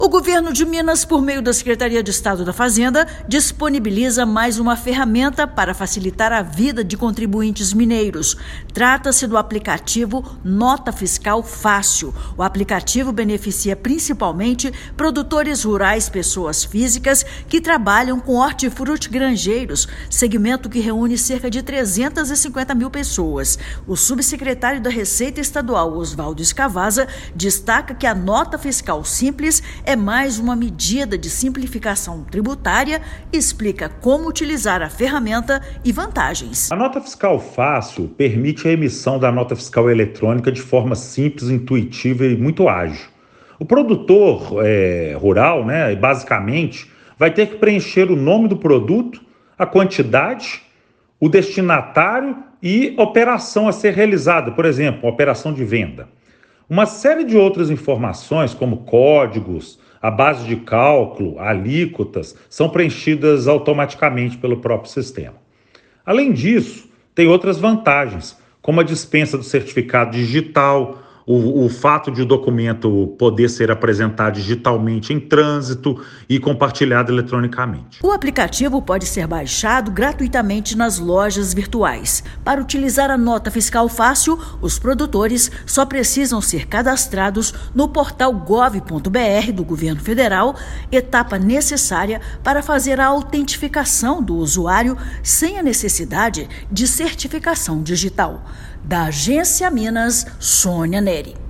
Trata-se do aplicativo Nota Fiscal Fácil (NFF). Ouça matéria de rádio.